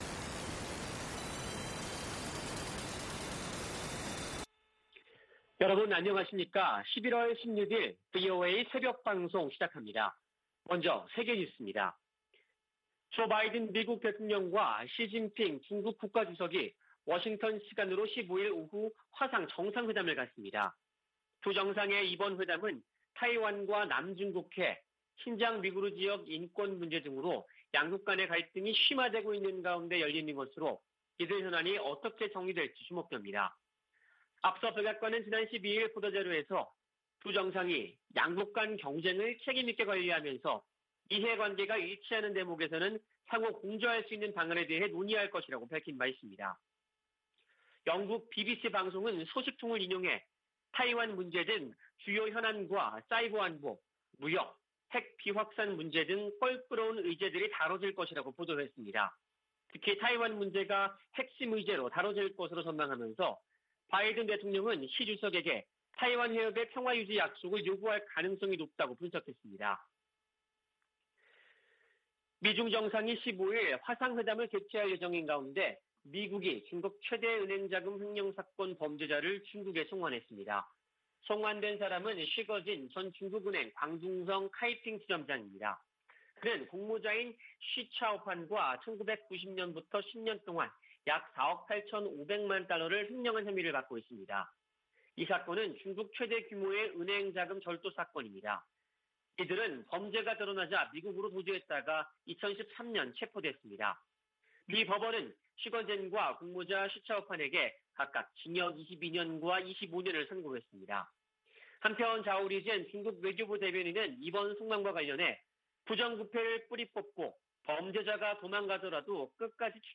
VOA 한국어 '출발 뉴스 쇼', 2021년 11월 16일 방송입니다. 미국과 한국 사이에 종전선언 논의가 이어지는 가운데 북한이 유엔군사령부 해체를 연이어 주장하고 있습니다. 미 국무부 동아시아태평양 담당 차관보는 미국이 북한에 전제조건 없는 대화 제안을 했으며, 북한의 답을 기다리고 있다고 말했습니다.